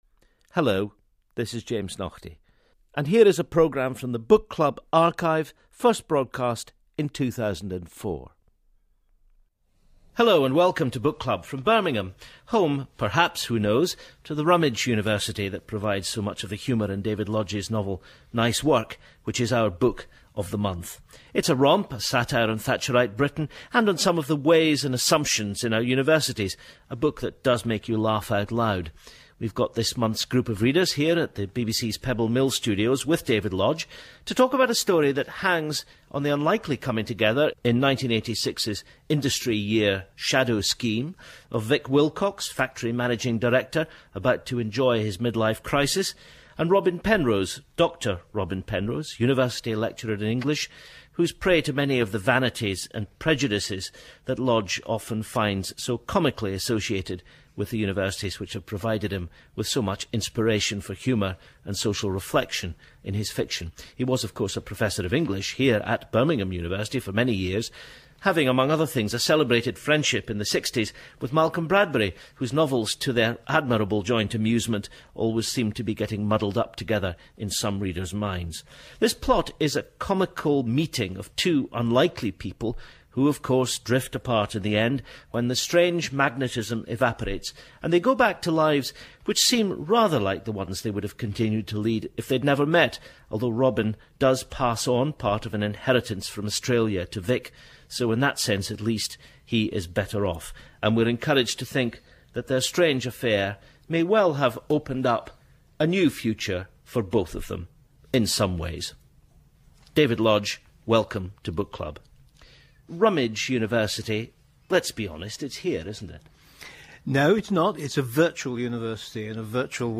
Interview with the novelist David Lodge on Nice Work